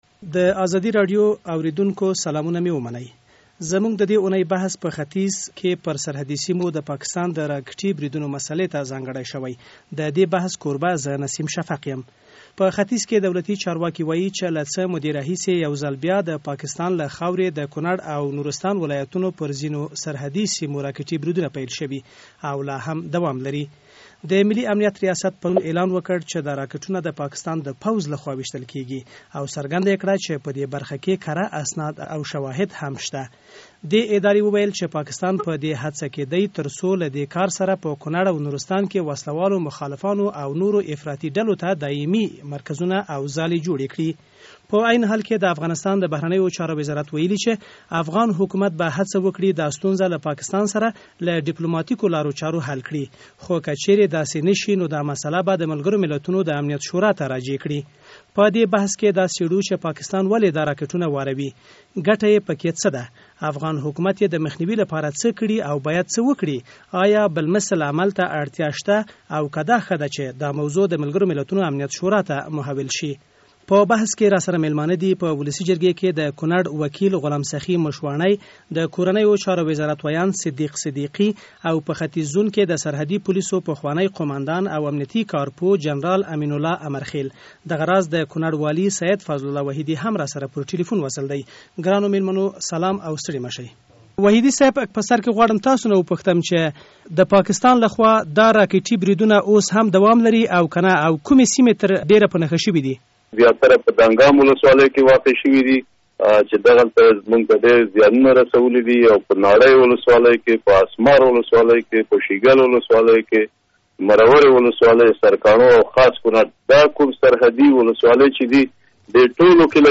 د ازادۍ راډیو د دې اونۍ بحث په ختیځ کې پر سرحدي سیمو د پاکستان د راکټي بریدونو مسالې ته ځانګړی شوی.